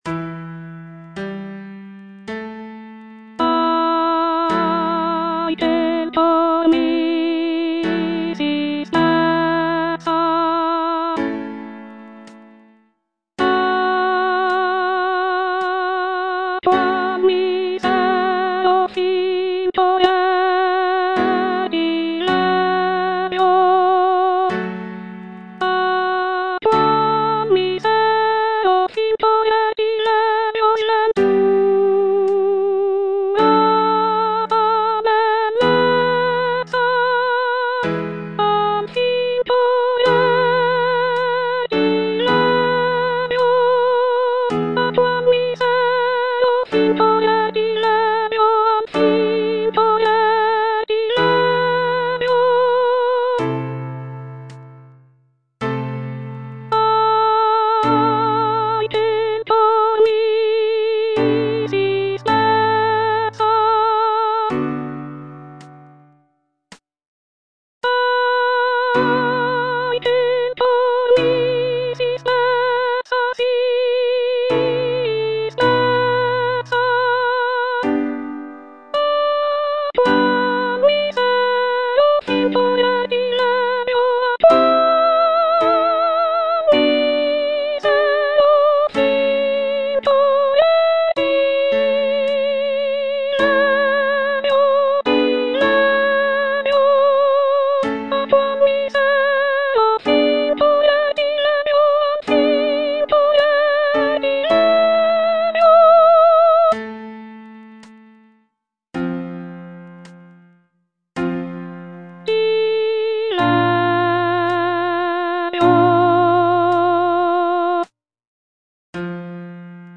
(soprano I) (Voice with metronome) Ads stop